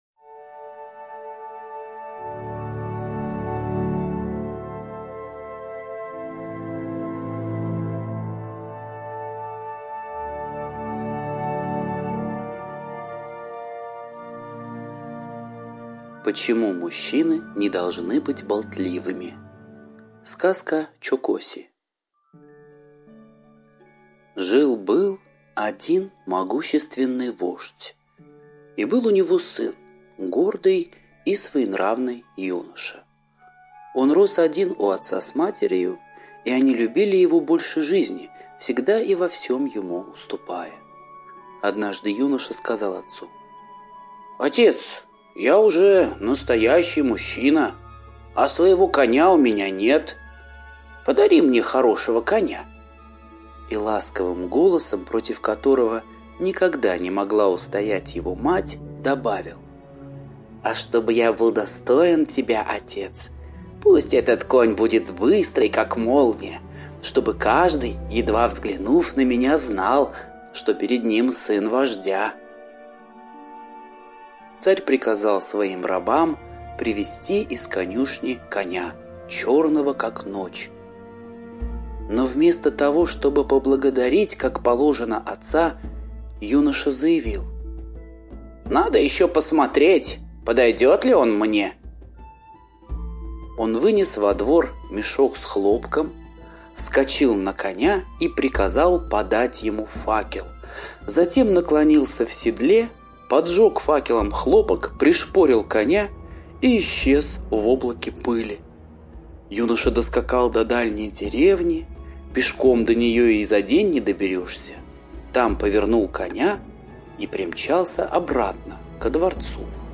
Слушайте Почему мужчины не должны быть болтливыми - восточная аудиосказка. Про юношу-сына вождя, который выбрал себе волшебного коня.